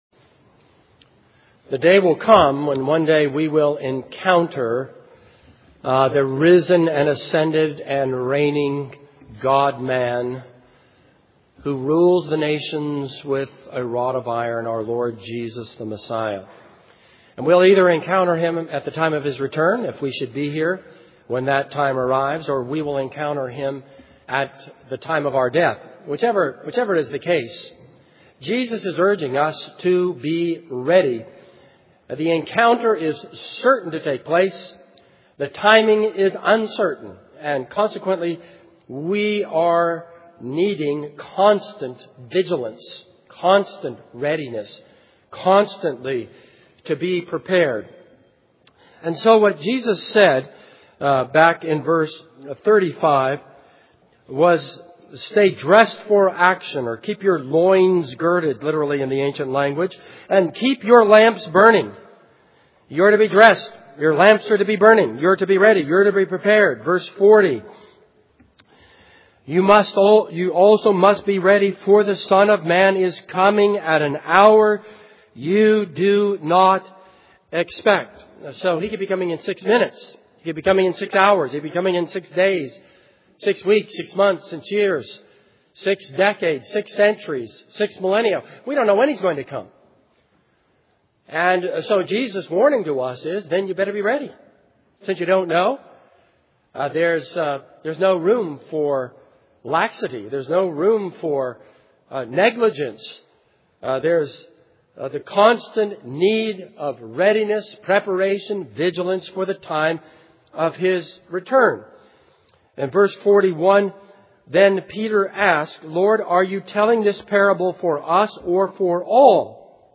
This is a sermon on Luke 12:35-48.